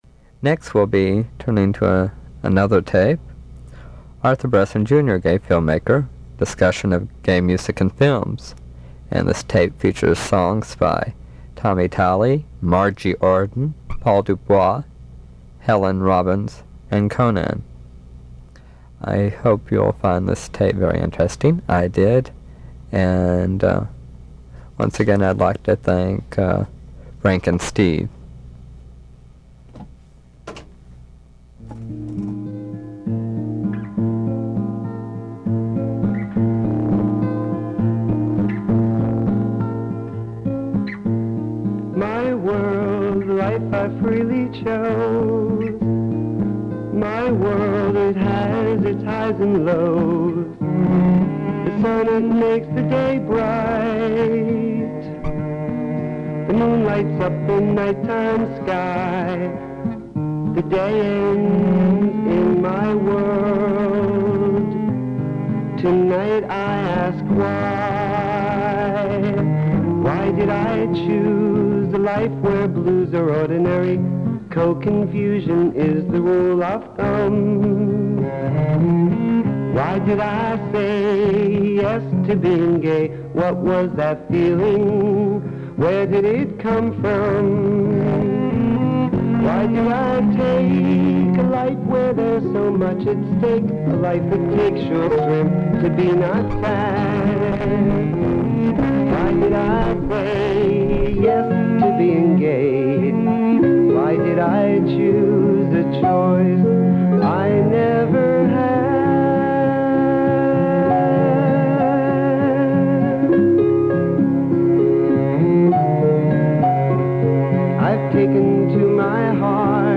Songs included in the interview